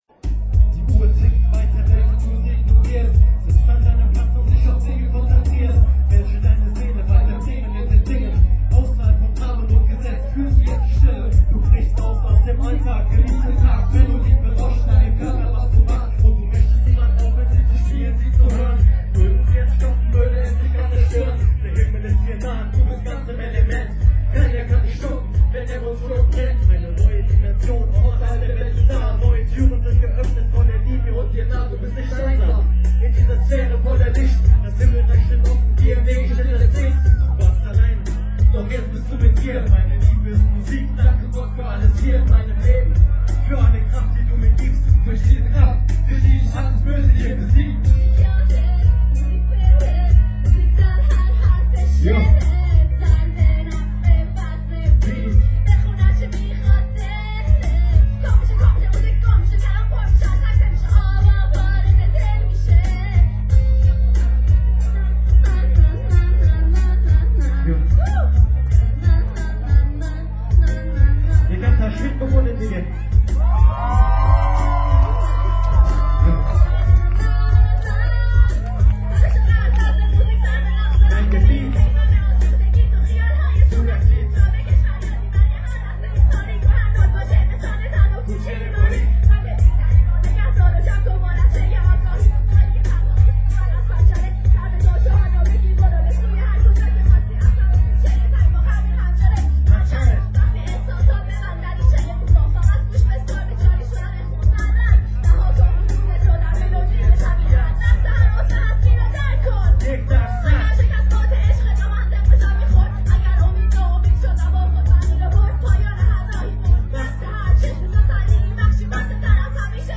جشنواره هلند
اجرای کنسرت